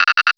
Cri de Grainipiot dans Pokémon Rubis et Saphir.